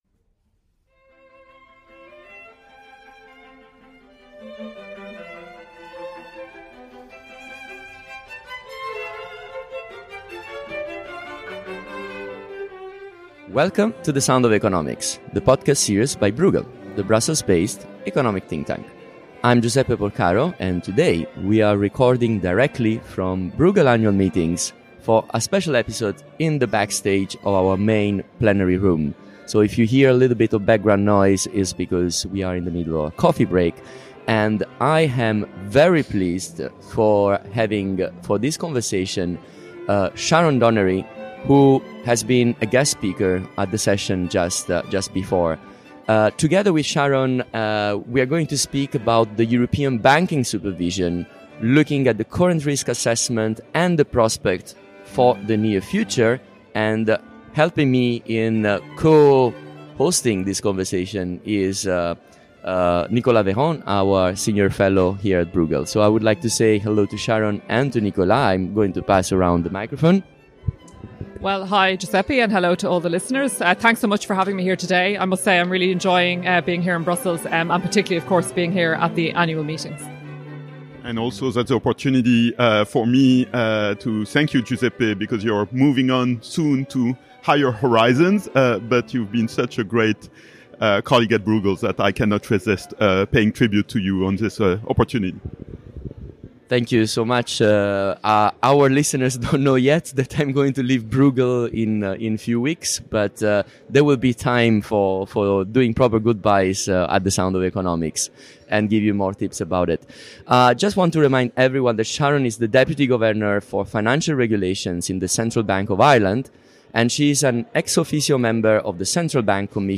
European banking supervision has developed and matured by moving from being predominantly rules-based and heavily codified, to becoming more risk-focused and adaptable to rapidly changing economic circumstances. Backstage at the Bruegel Annual Meetings 2023